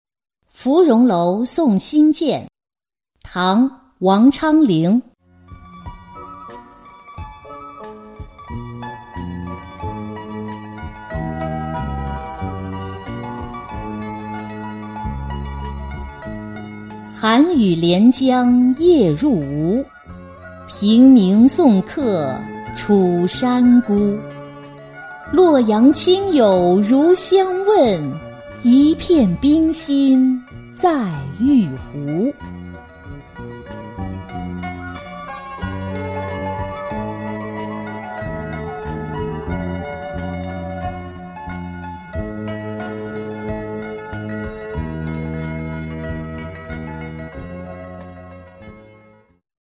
芙蓉楼送辛渐-音频朗读